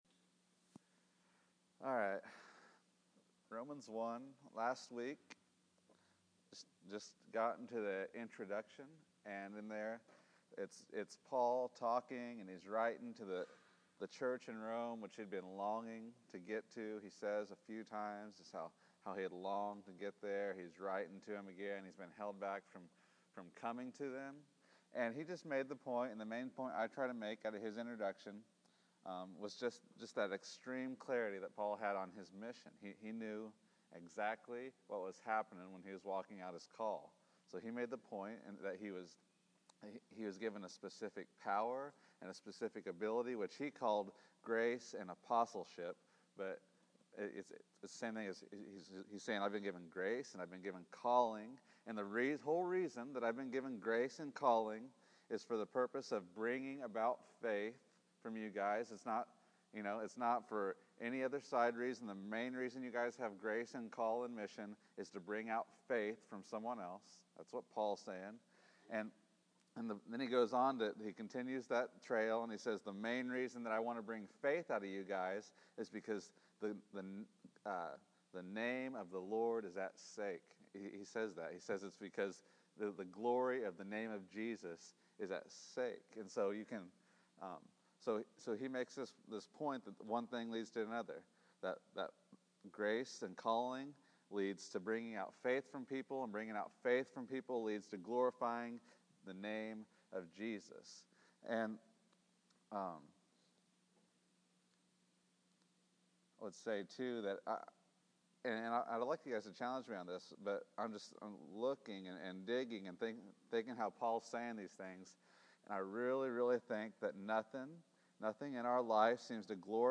Romans 1:8-14 October 26, 2014 Category: Scripture Teachings | Location: El Dorado Back to the Resource Library Paul’s longing to fellowship originates in his desire for faith.